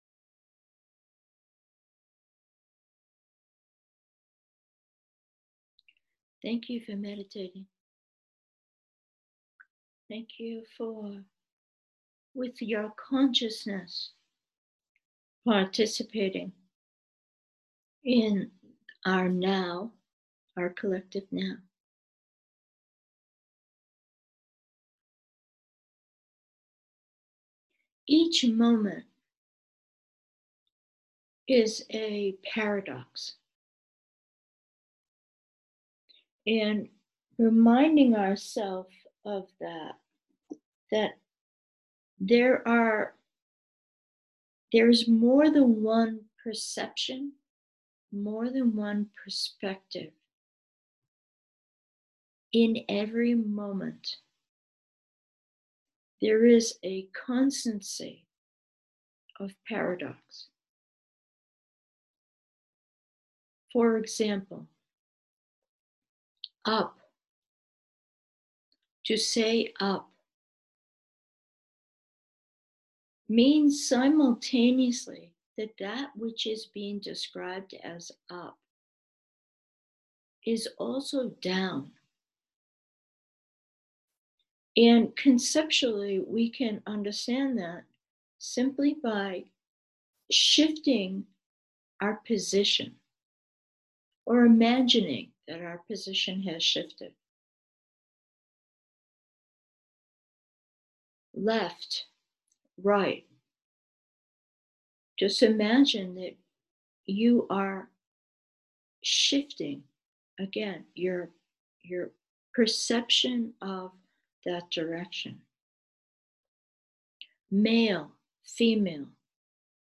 Meditation: arbitrary, dissolve